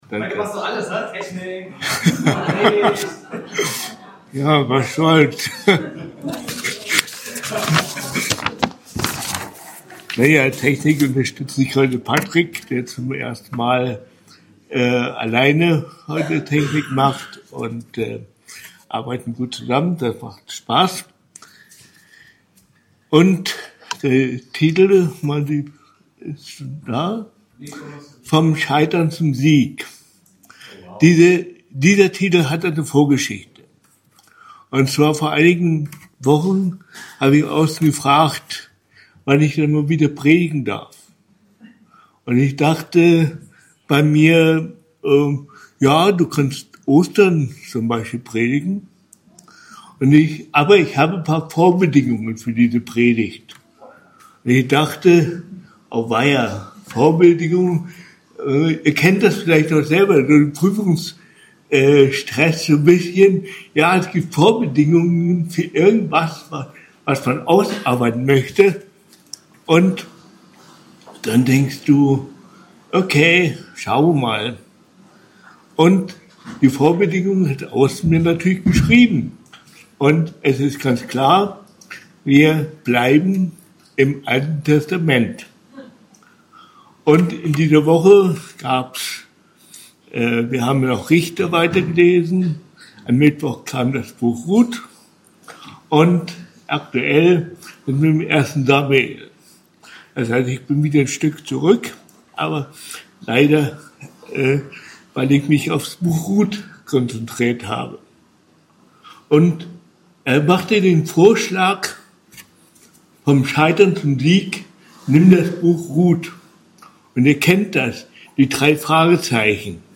Vom Scheitern zum Sieg ~ BGC Predigten Gottesdienst Podcast